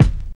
VINYL 1.wav